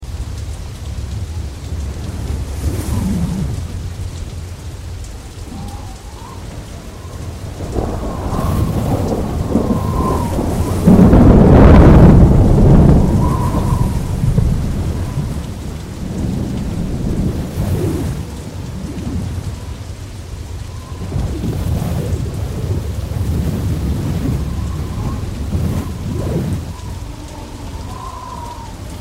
Ses Efektleri